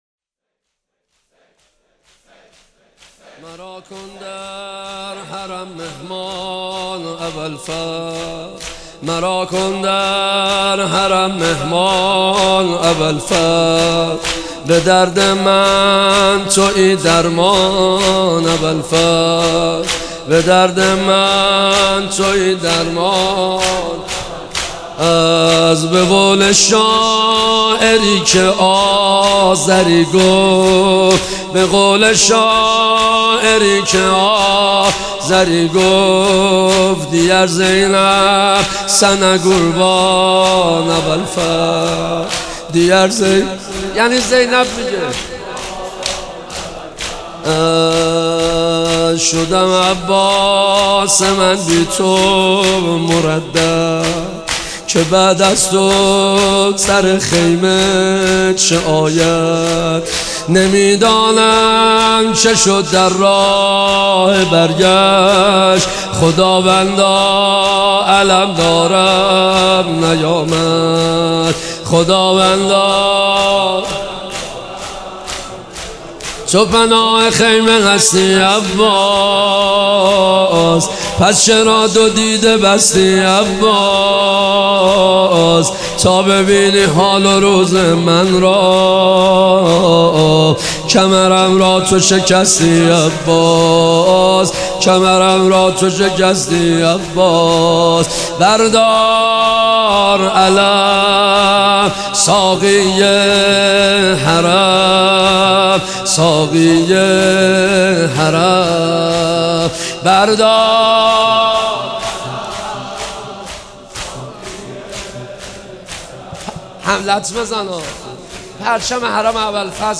واحد ولادت حضرت اباالفضل العباس(ع)